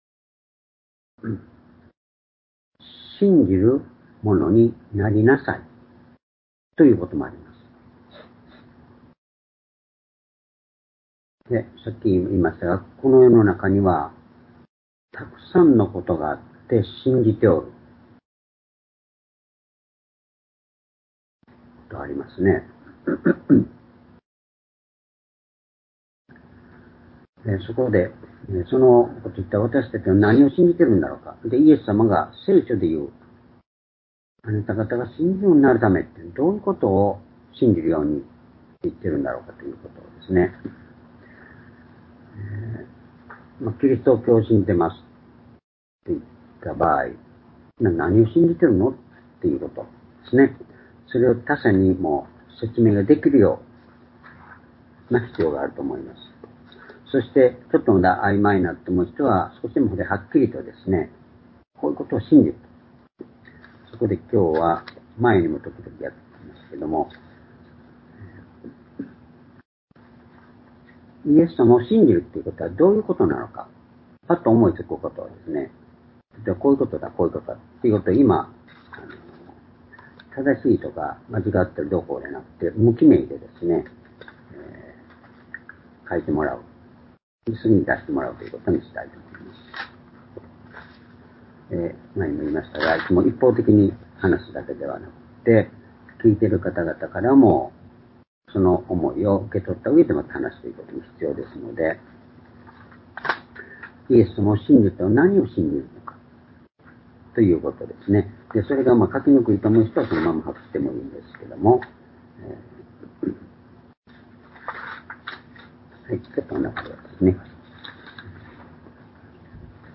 主日礼拝日時 2023年7月30日(主日) 聖書講話箇所 「信じるものになるために」 ヨハネ11章11～16節 ※視聴できない場合は をクリックしてください。